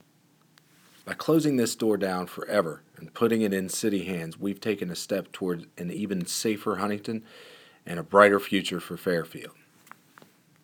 Click the links below to listen to audio clips from U.S. Attorney Booth Goodwin regarding today’s guilty plea: